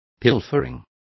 Also find out how sisa is pronounced correctly.